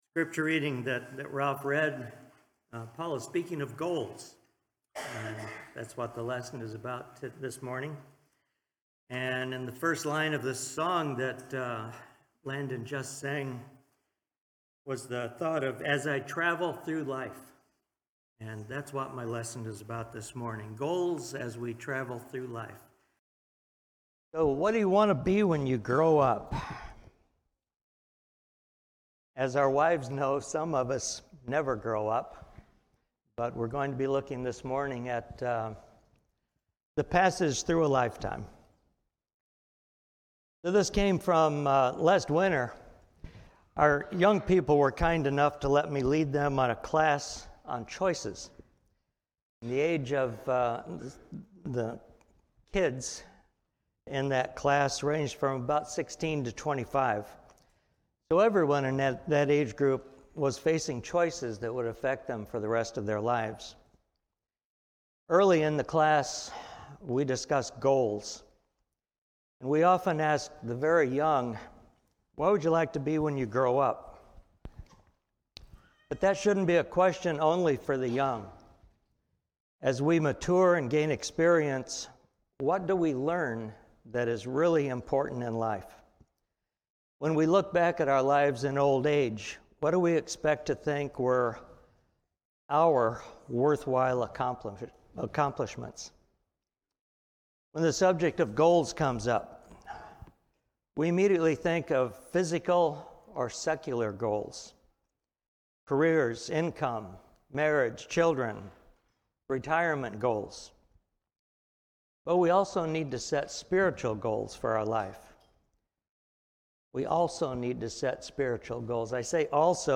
A lesson on how our physical goals affect our ultimate spiritual goal of success.